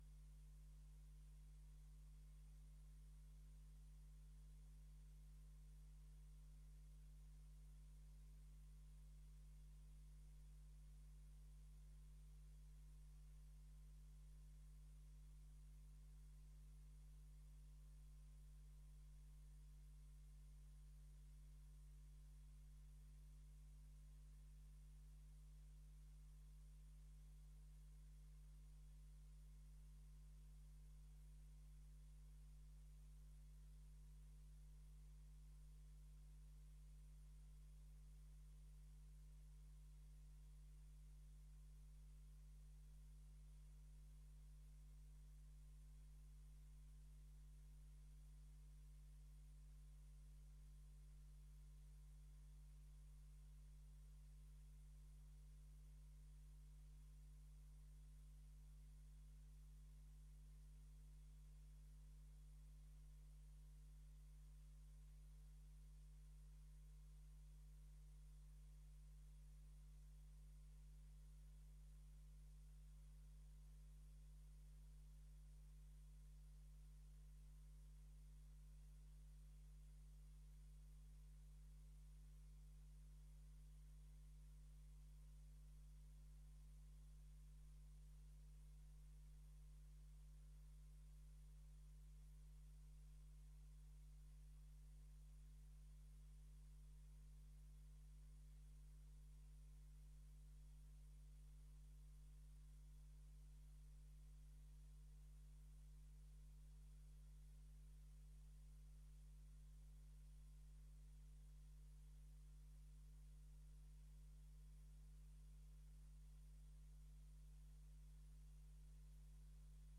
Locatie: Raadzaal
Welkomsttoespraken
Installatierede burgemeester